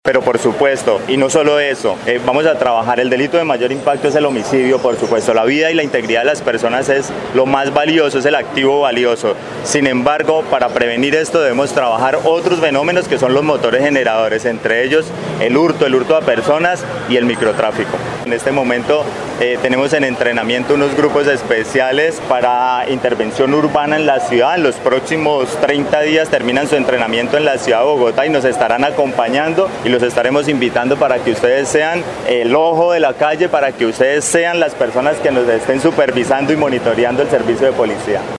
Así lo dio a conocer el comandante de la Policía Caquetá, coronel, Cesar Pinzón Higuera.